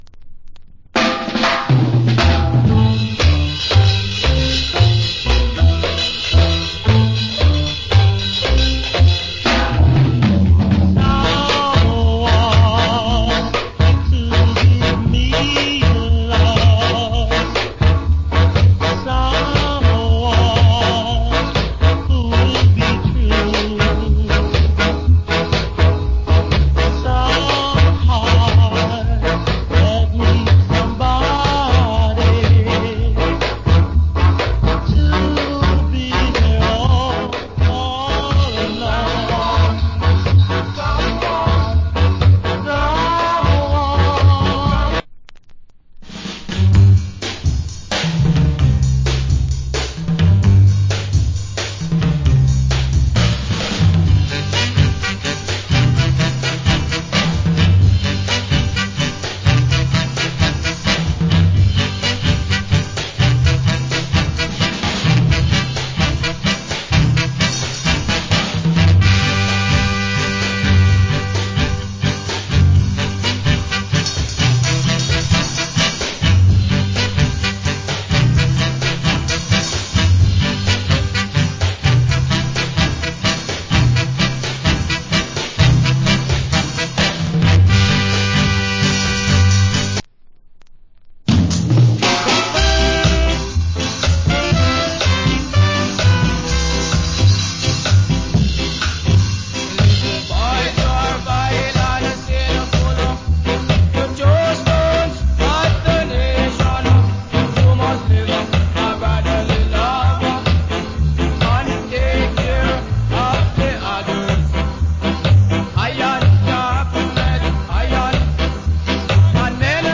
Great Ska Vocal & Inst Double. 2003.